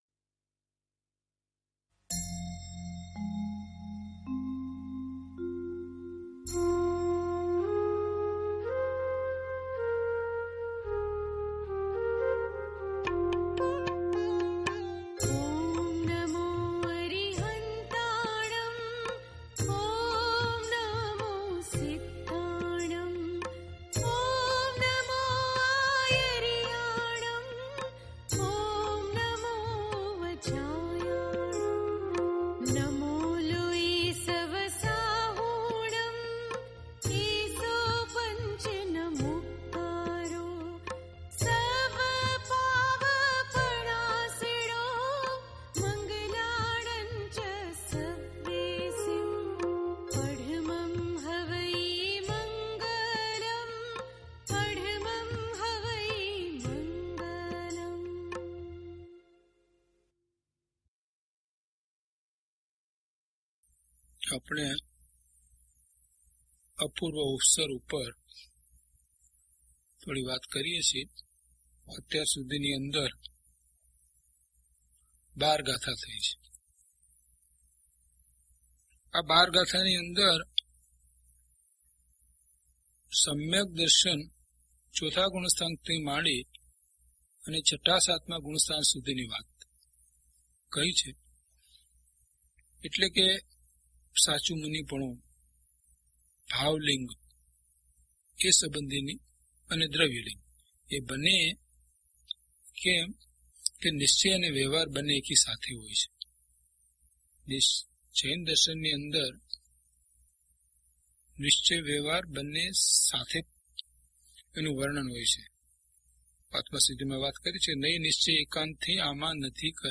Audio title: DHP009 Apurva Avasar Gatha 13 to 21 - Pravachan.mp3